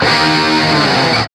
Index of /90_sSampleCDs/Roland L-CDX-01/GTR_GTR FX/GTR_Gtr Hits 1
GTR DIG D0ML.wav